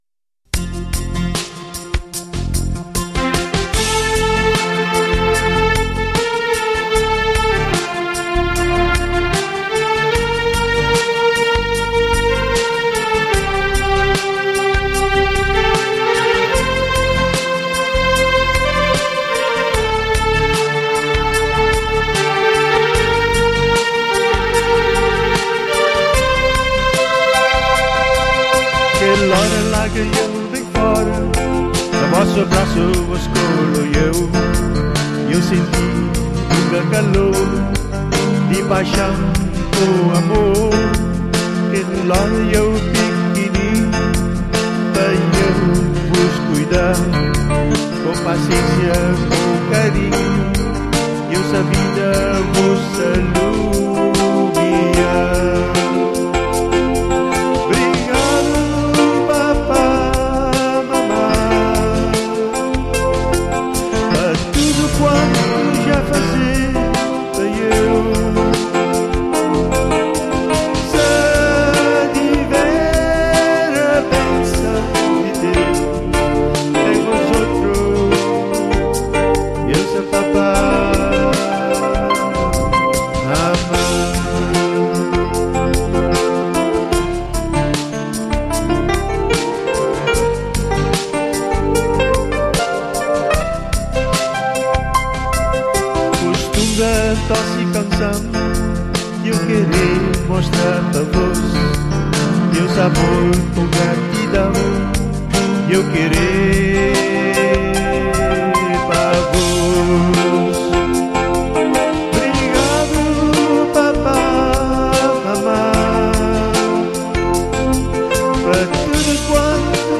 e acabaram por produzir um fundo musical mais alegre e tal como queria